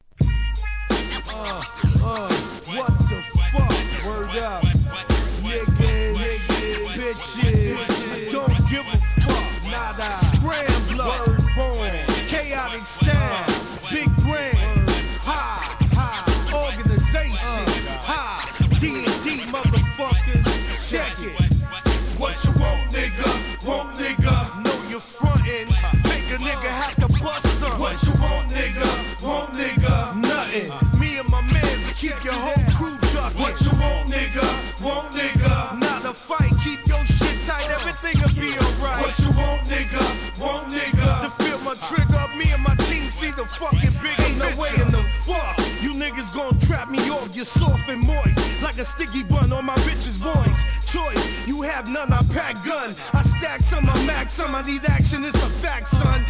1. HIP HOP/R&B
1995年、ハーコー・アングラ!!